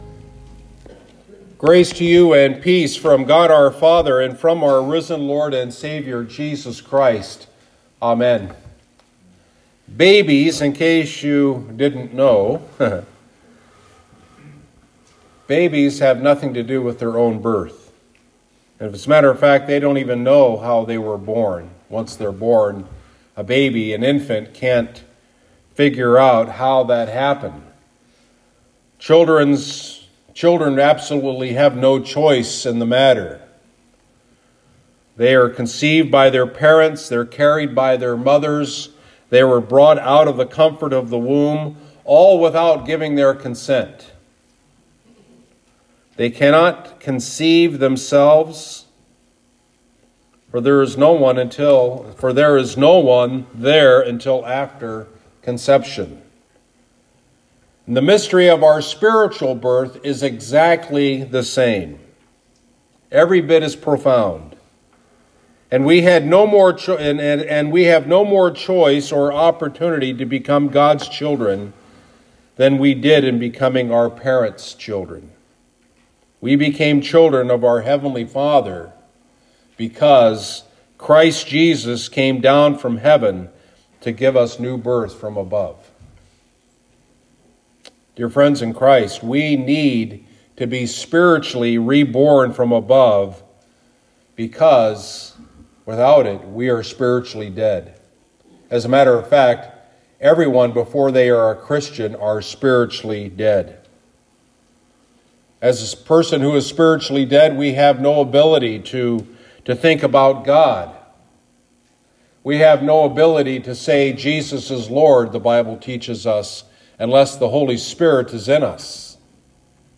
Lent 2 Sermon — “Born from Above”